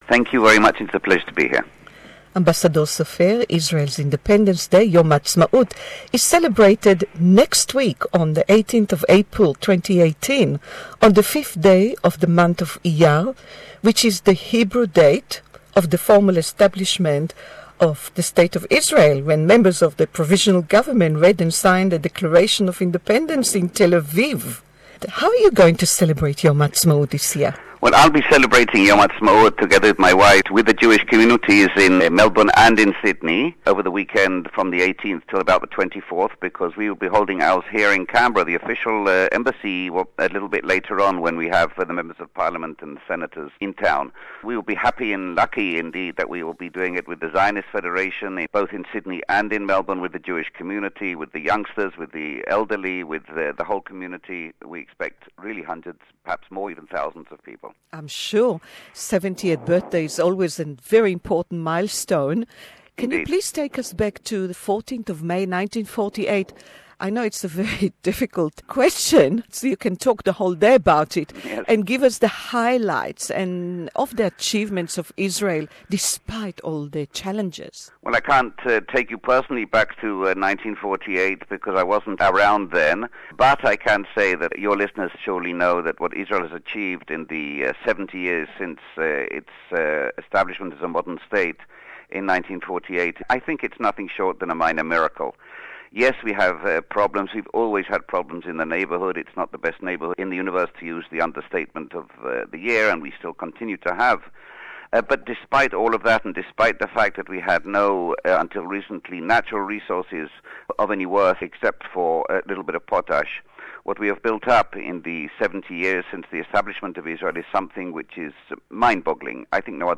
Don't miss an exclusive interview with Israel's Ambassador to Australia who sees Israel after 70 years, as a miracle with fantastic achievements, despite all the challenges...